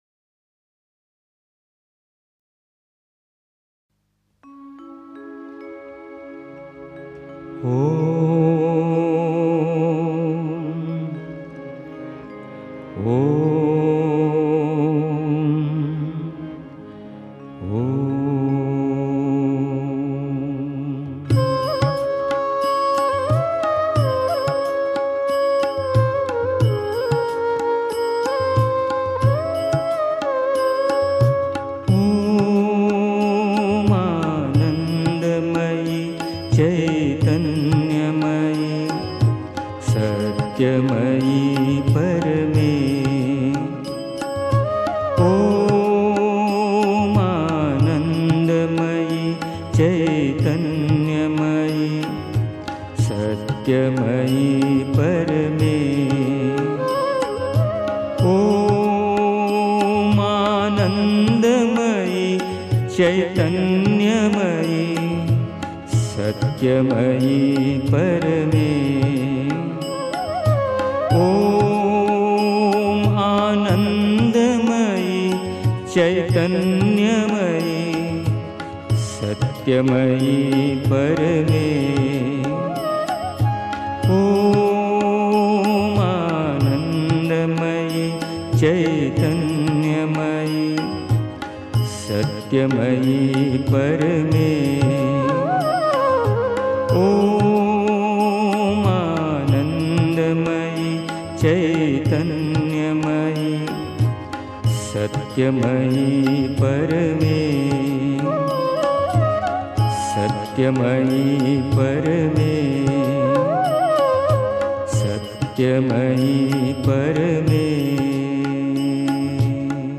1. Einstimmung mit Musik. 2. Wenn du zum Yoga kommst (Die Mutter, CWM, Vol. 3, pp. 83-84) 3. Zwölf Minuten Stille.